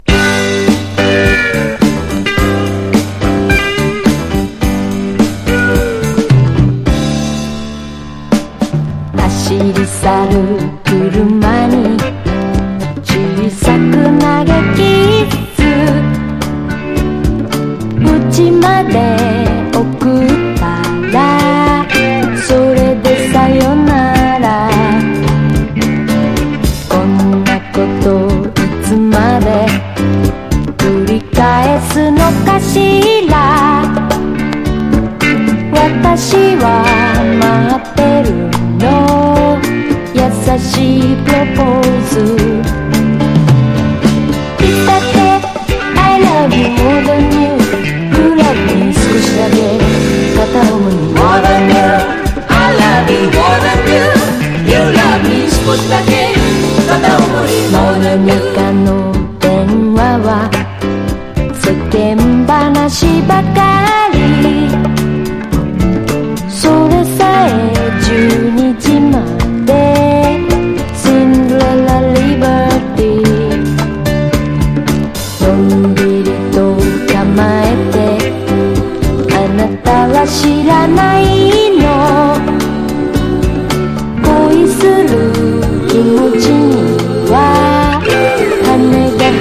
SSW / FOLK